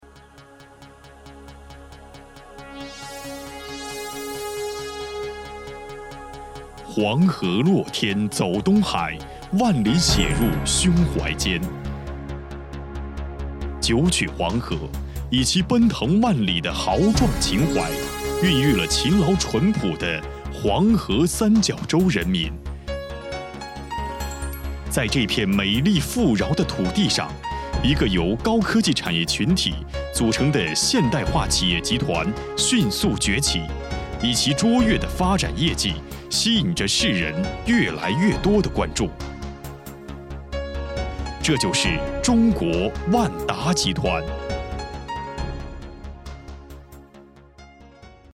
Audio en chino para empresas, la siguiente locución narrada por una voz masculina es un anuncio publicitario para el Grupo Wanda.
Locutor-varón-chino-8.mp3